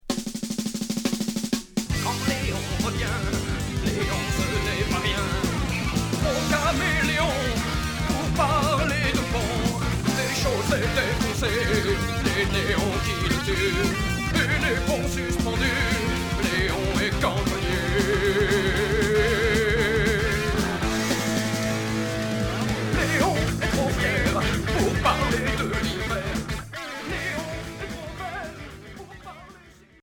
Rock hard Unique 45t retour à l'accueil